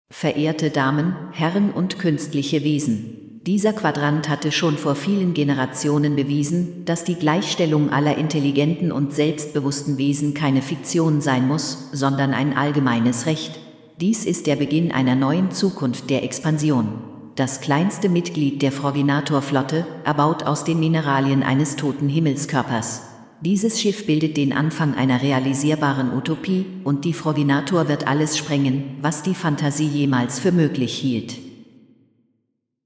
Ich habe verschiede Sprachsynthesen für die Vertonung getestet.
Test - IBM Watson:
Watson_Sprachtest.mp3